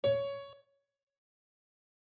CSharp_DODiese.mp3